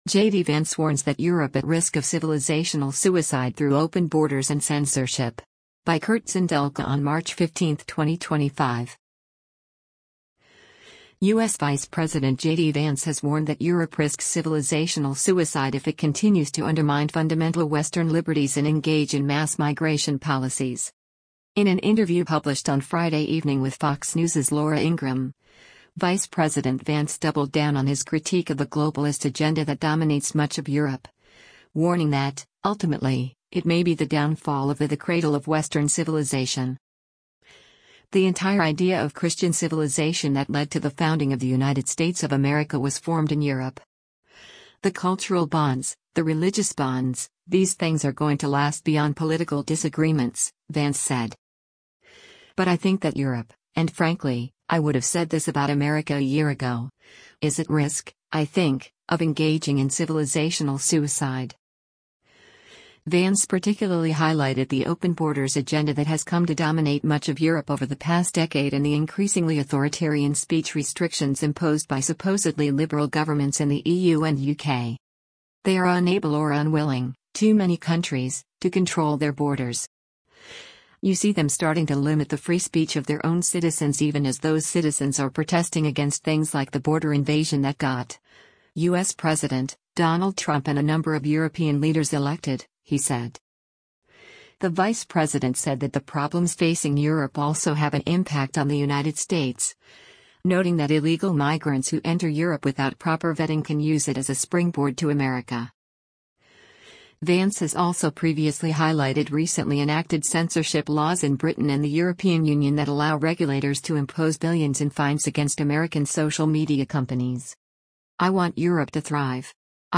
In an interview published on Friday evening with Fox News’s Laura Ingraham, Vice President Vance doubled down on his critique of the globalist agenda that dominates much of Europe, warning that, ultimately, it may be the downfall of the “the cradle of Western civilization”.